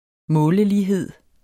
Udtale [ ˈmɔːləliˌheðˀ ]